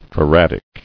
[fa·rad·ic]